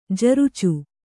♪ jarucu